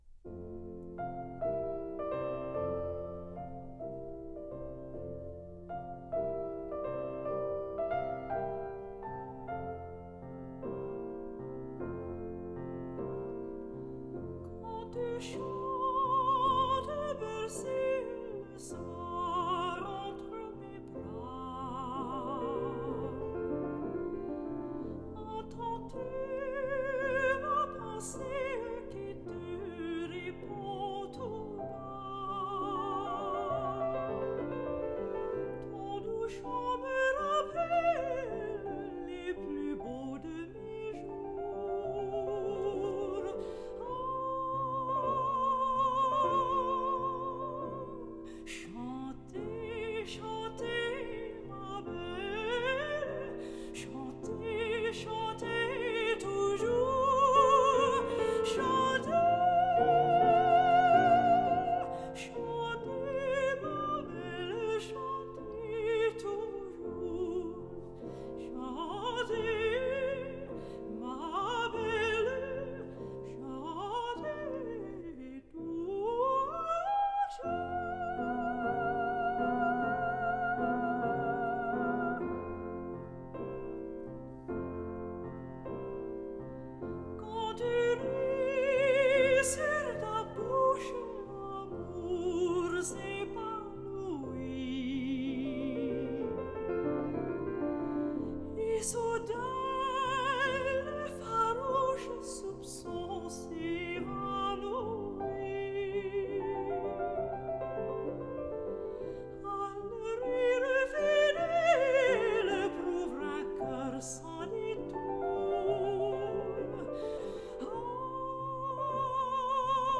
古诺的小夜曲 雨果词/古诺曲/奥格唱 古诺这首雨果诗谱成的独唱曲，亚琳.奥格唱的让人要醉了的感觉!
法国的抒情气息温柔极了，对照托斯蒂的“飞吧小夜曲”来听，后者的情感就浓烈了！